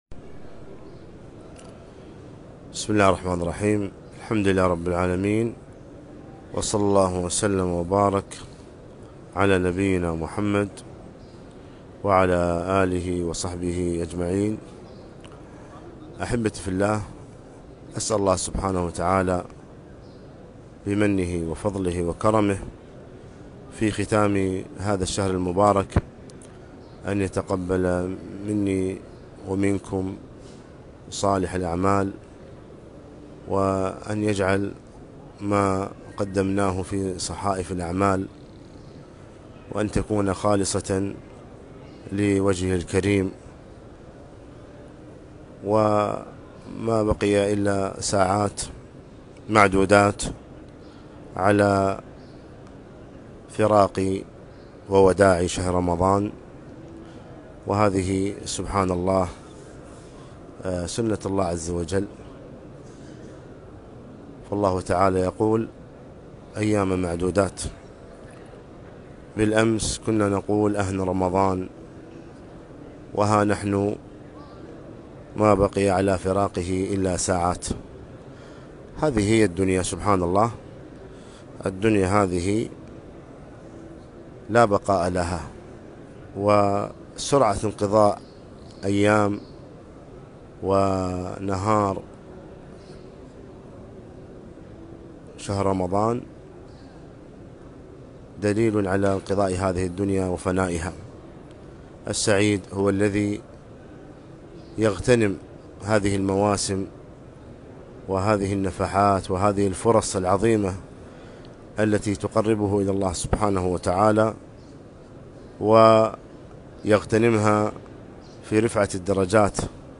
كلمة - ما يشرع في ختام شهر رمضان